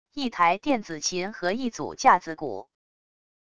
一台电子琴和一组架子鼓wav音频